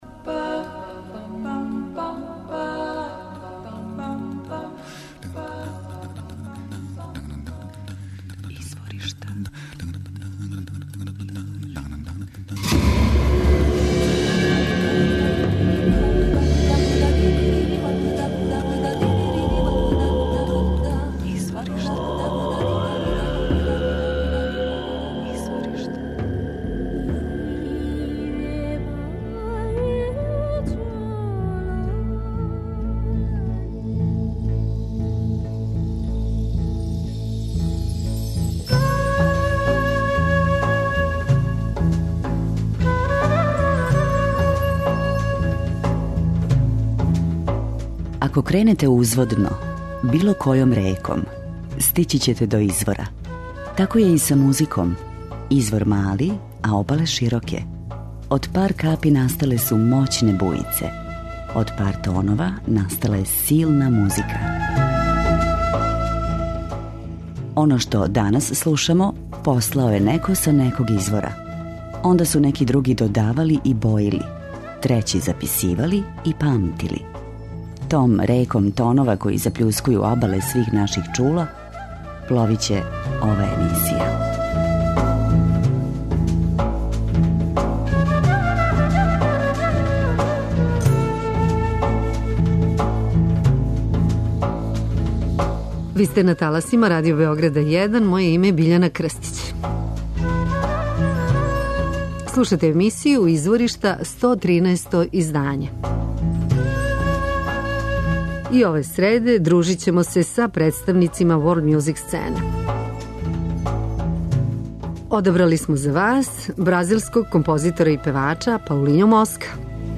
И ове среде дружимо се са уметницима World music сцене.
Чарлс Камилери компонује музику која је једноставна и непогрешиво малтешка. У песмама осликава фолклорне и модерне теме.